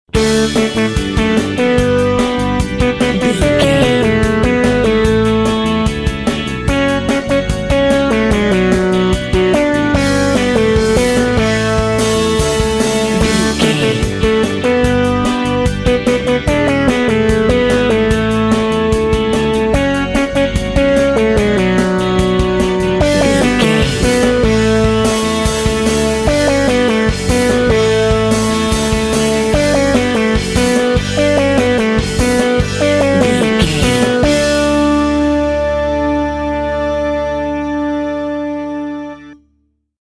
Kids Theme Music.
Ionian/Major
D
Fast
instrumental music
electronic
drum machine
synths
strings
orchestral
brass